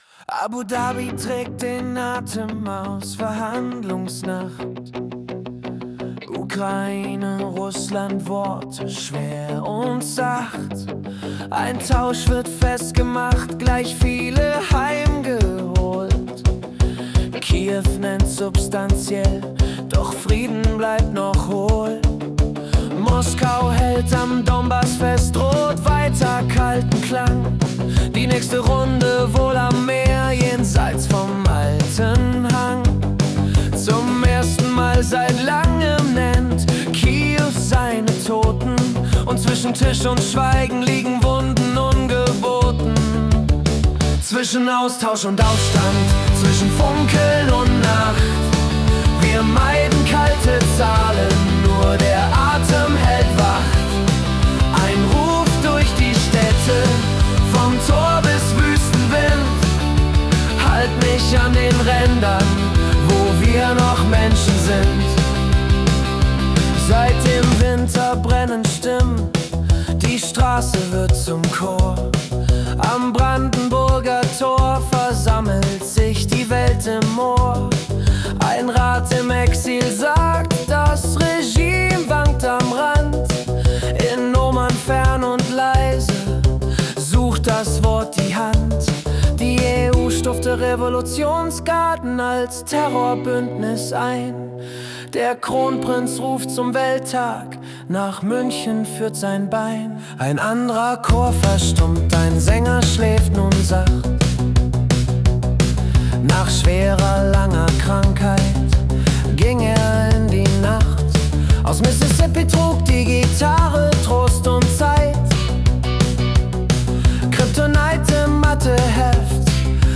Die Nachrichten vom 8. Februar 2026 als Singer-Songwriter-Song interpretiert.